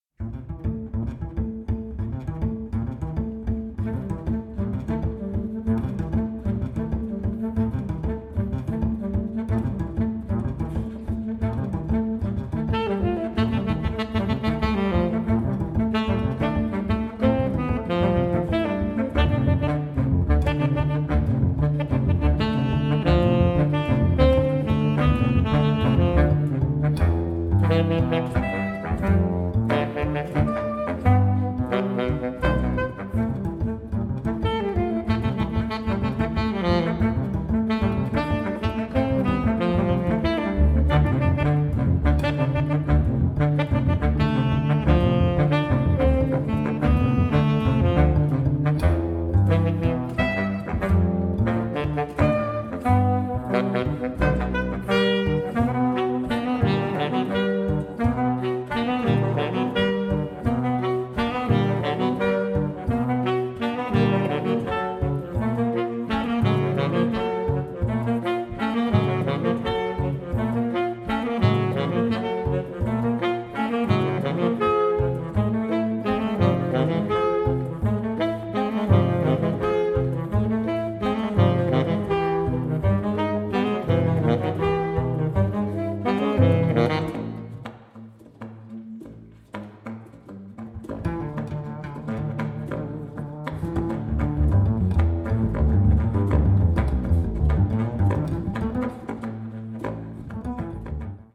soprano, alto & tenor saxophones
double bass
bass saxophone